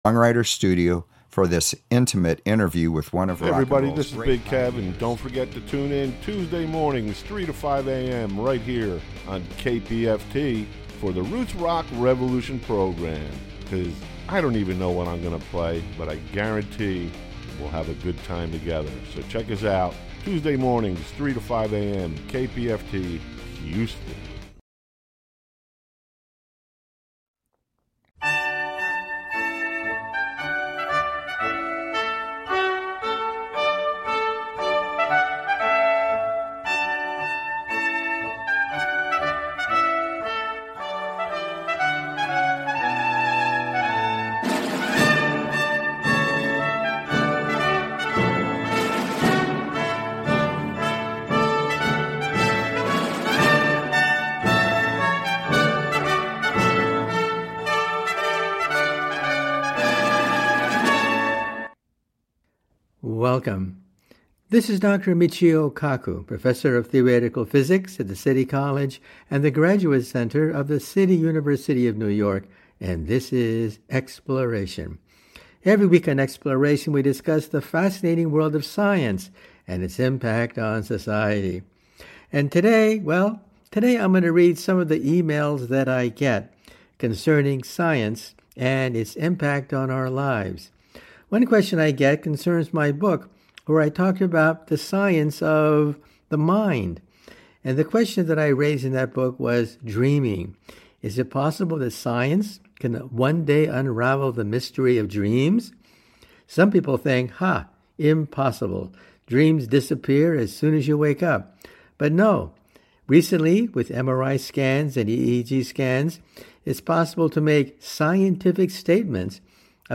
On Air Candidate Forums
Tune in to your station to hear candidate statements and ask questions to the listener candidates running to represent you.